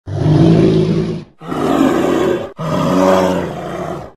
Beruang_Suara.ogg